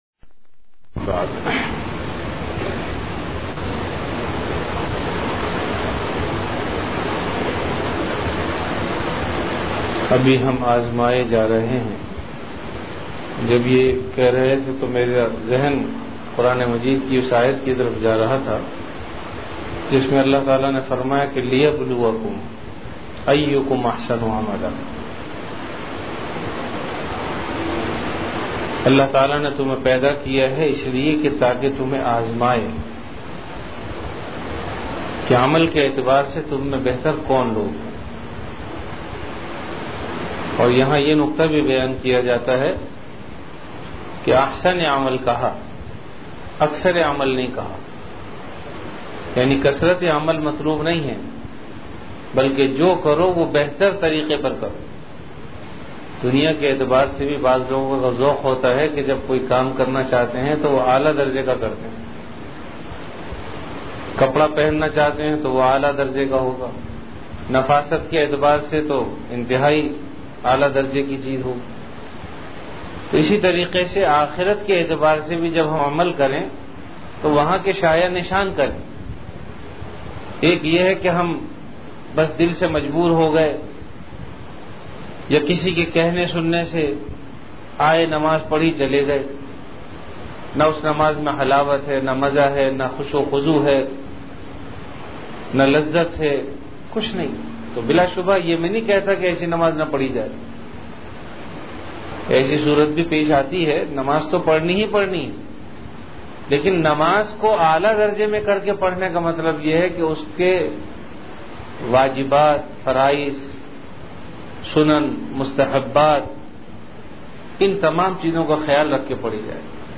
Majlis-e-Zikr